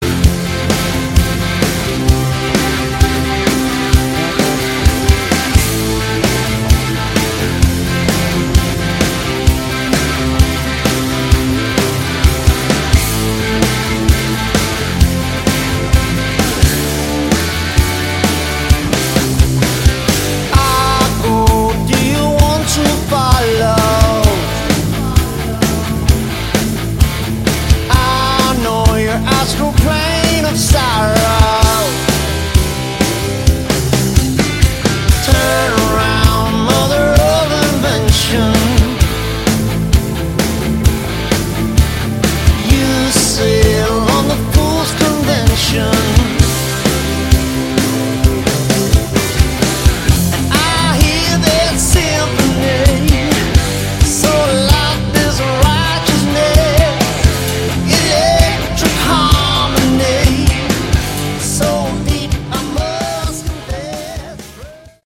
Category: Hard Rock
guitars
vocals
bass, keyboards
drums